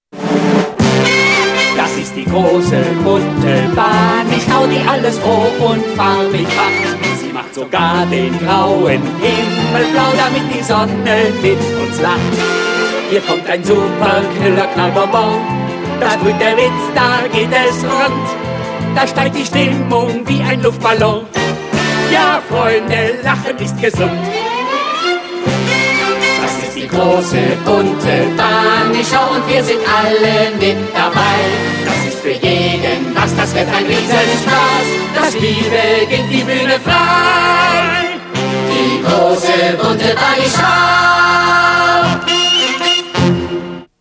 Der gute alte Intro-Song